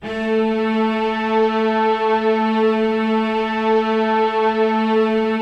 Index of /90_sSampleCDs/Optical Media International - Sonic Images Library/SI1_Lush Strings/SI1_Lush Mix
SI1 LUSH 05R.wav